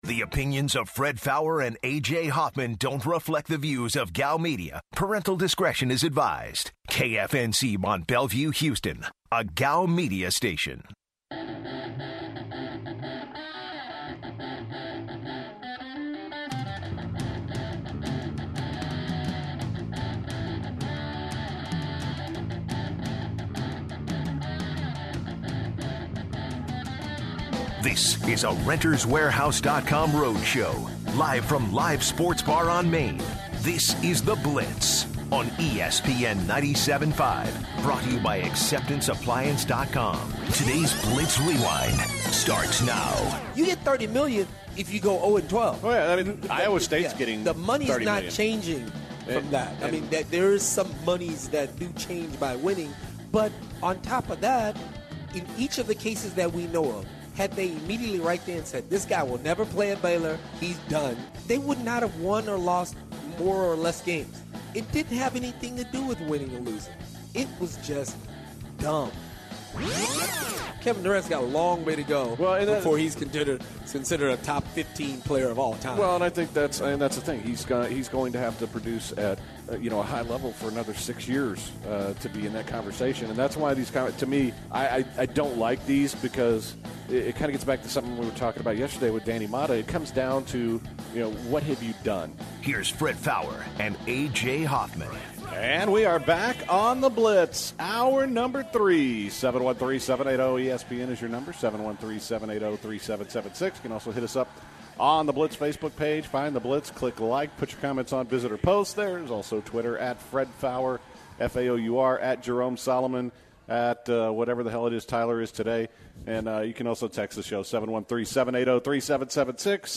Wrapping up the final hour of The Blitz from Live Sports Bar & Grill